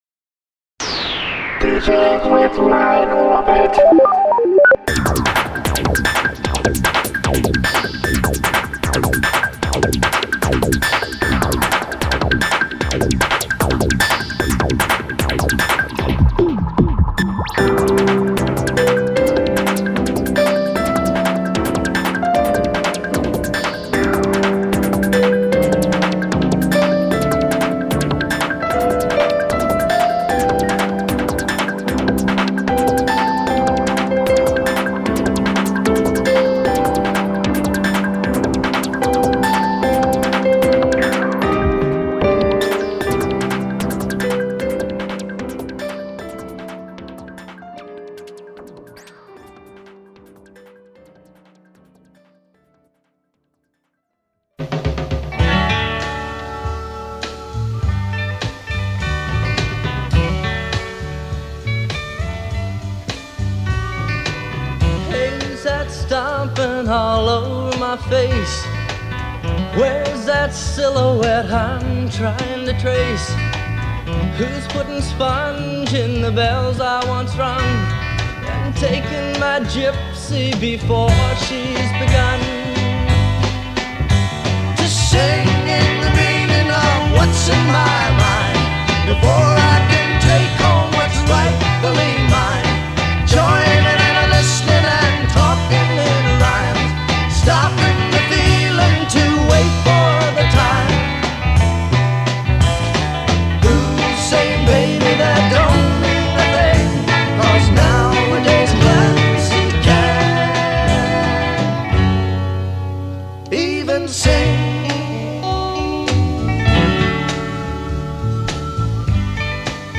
Today’s TMBR focused on Americana, Folk, Country, Rock..etc. Please relax and enjoy.